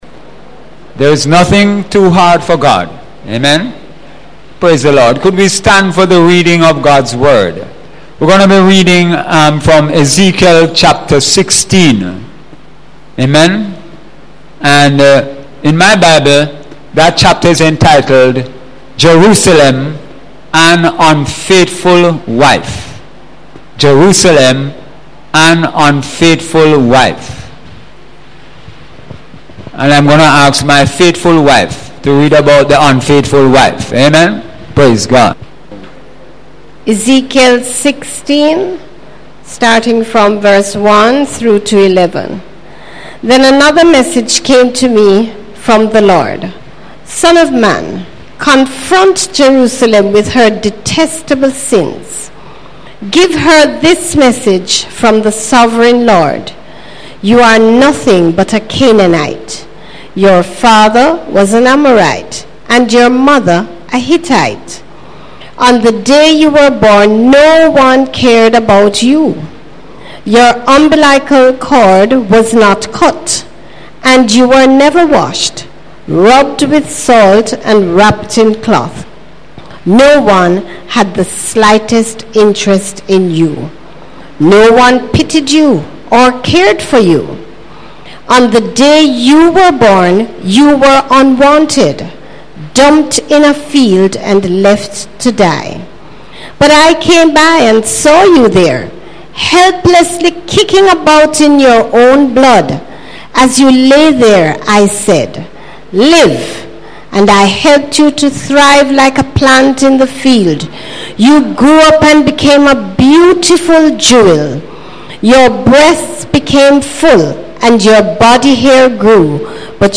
Sunday Sermon – April 23, 2017 – Daughters of Jerusalem